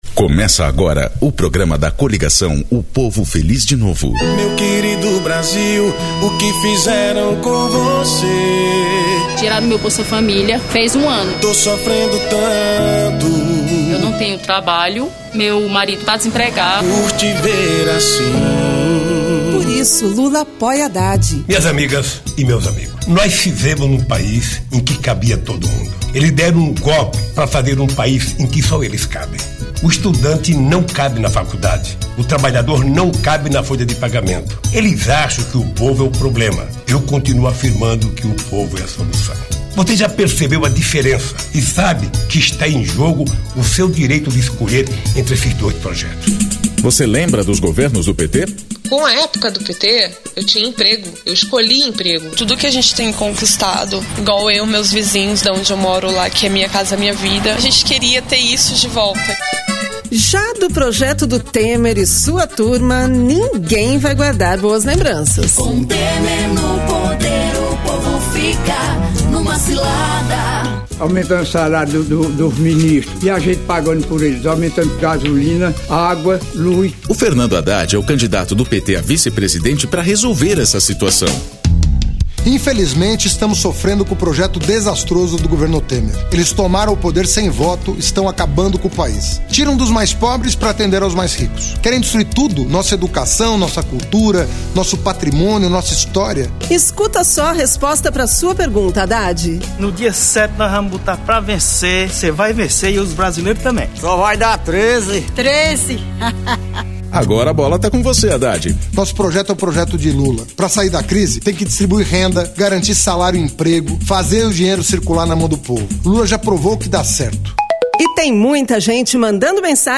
TítuloPrograma de rádio da campanha de 2018 (edição 07)
Gênero documentaldocumento sonoro